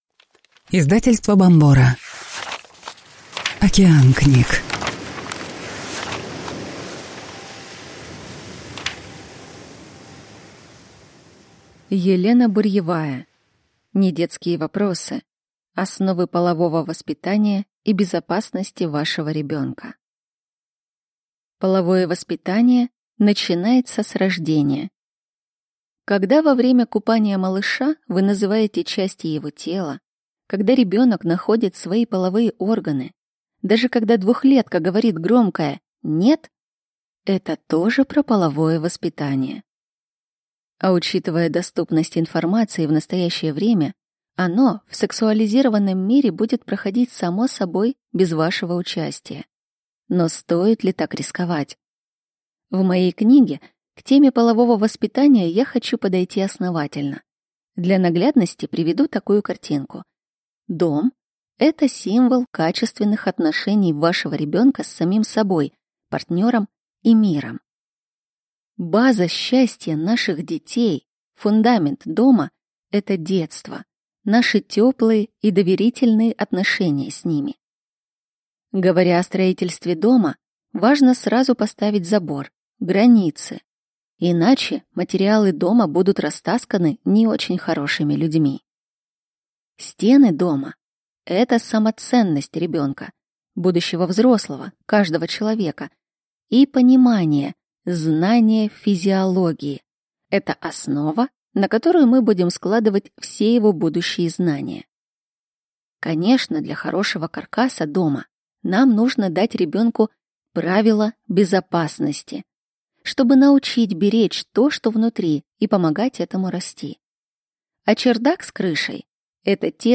Аудиокнига Недетские вопросы. Основы полового воспитания и безопасности вашего ребенка | Библиотека аудиокниг